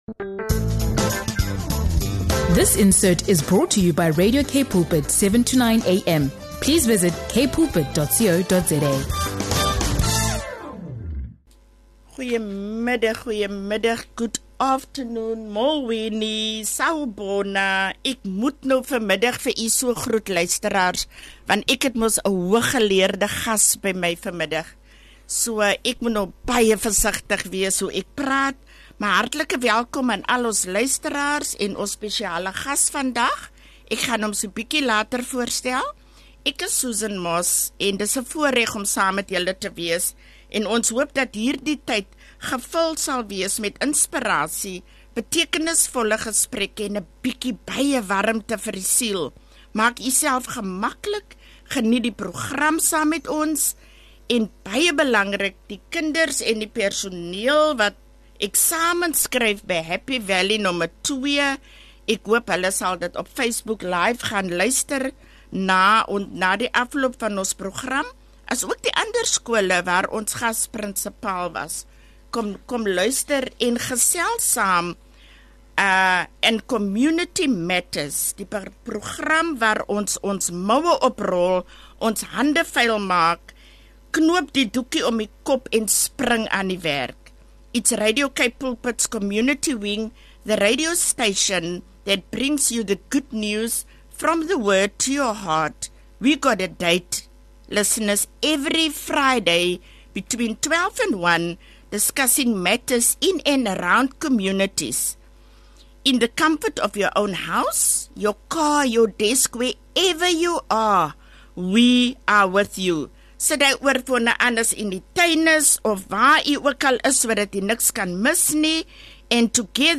Tune in for an uplifting conversation about leadership, education, and the calling to make a difference in the community.